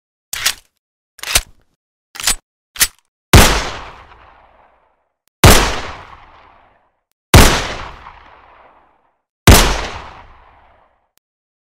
دانلود صدای کلت از ساعد نیوز با لینک مستقیم و کیفیت بالا
جلوه های صوتی